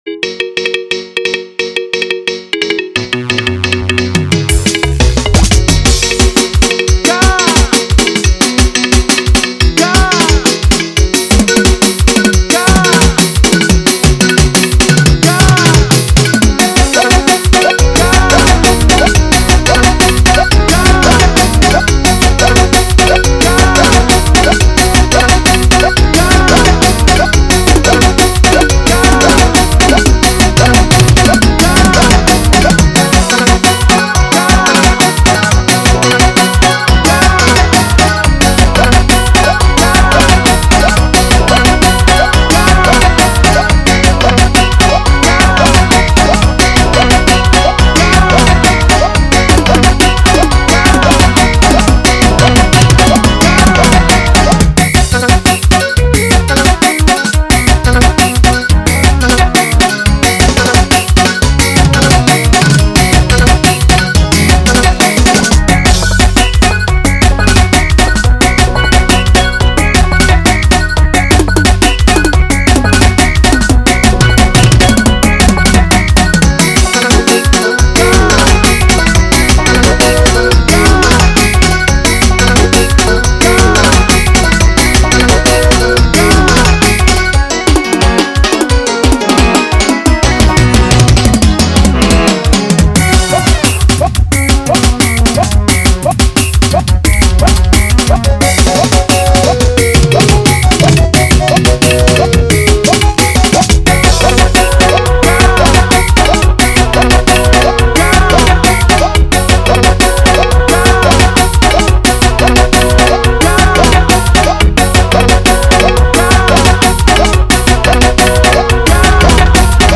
Genre : Xitsonga